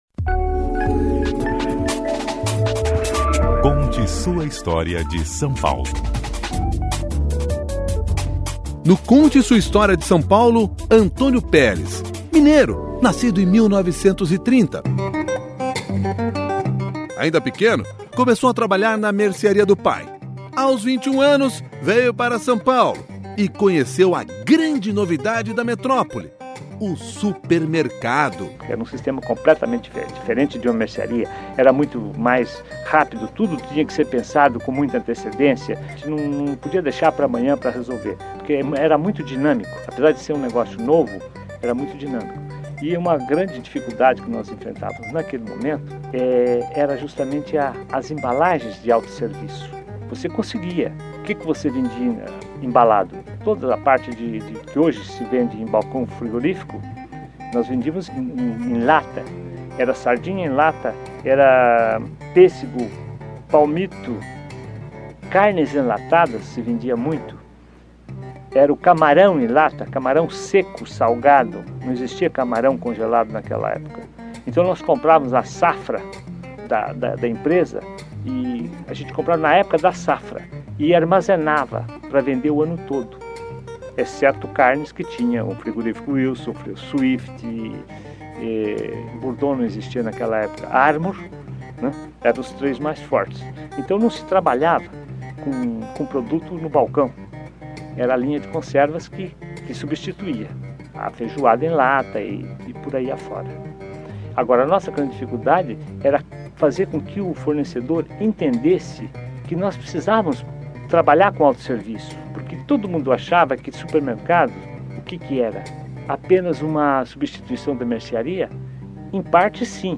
Um capítulo que ele descreveu em depoimento gravado pelo Museu da Pessoa e se transformou em mais um Conte Sua História de São Paulo: